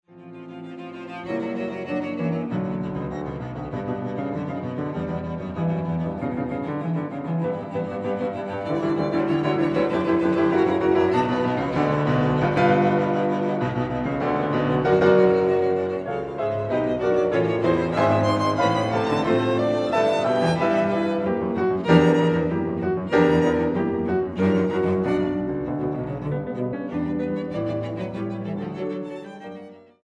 Grabado del 2 al 6 de Septiembre de 2013, Sala Xochipilli
Piano: Bechstein